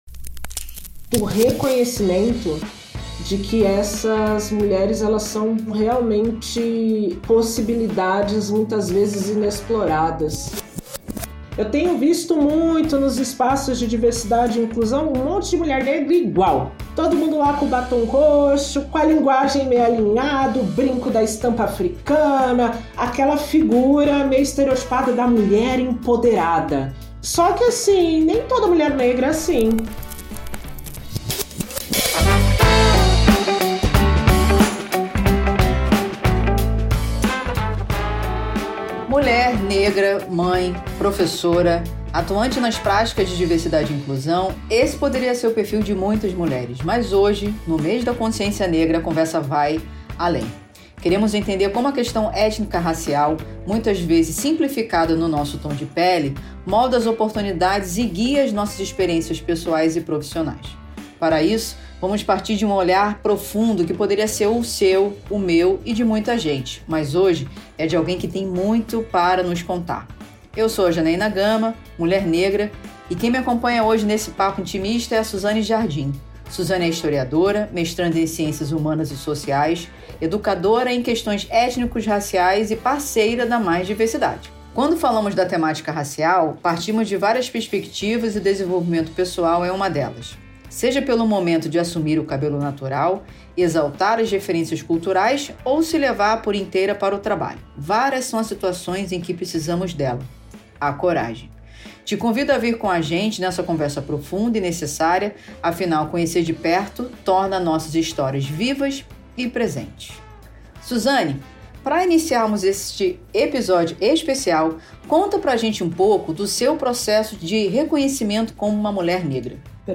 Foi um bate papo impactante!